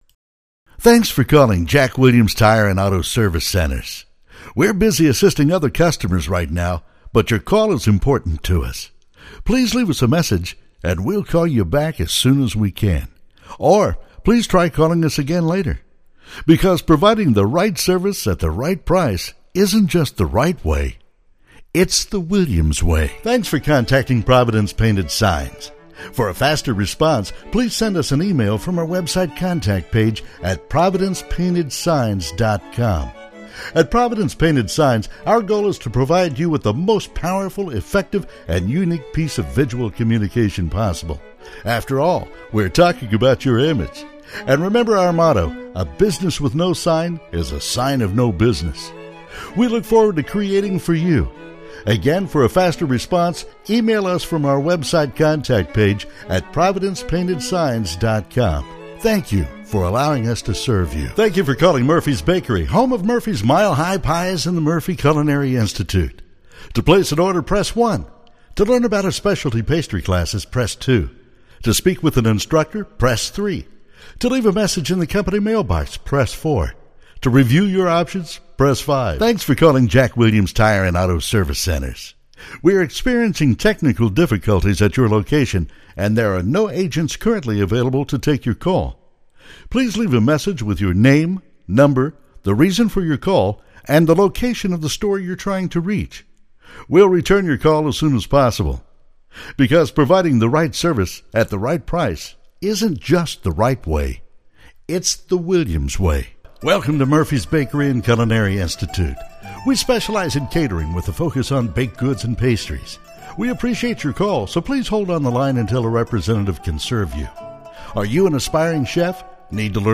Male
My voice ranges from deep Bass to Baritone.
Phone Greetings / On Hold
Actual Telephone Ivr Clients
Words that describe my voice are Deep, Southern, Cowboy.